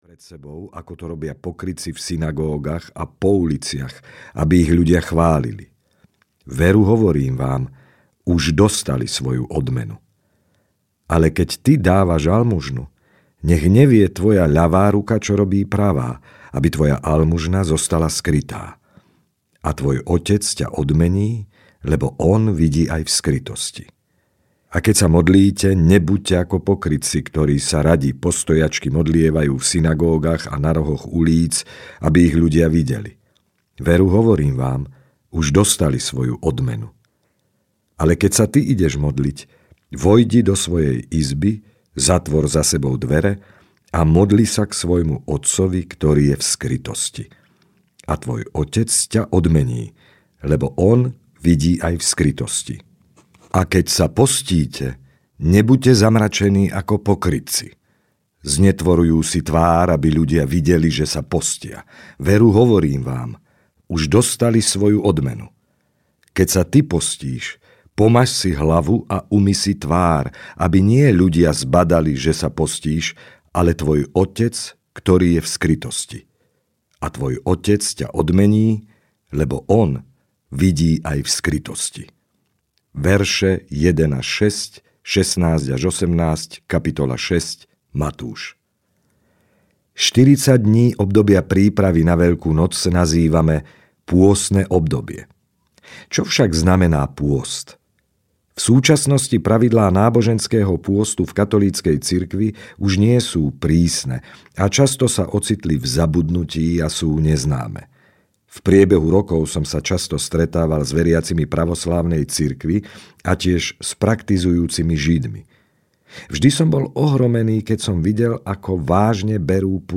Stopa do života audiokniha
Ukázka z knihy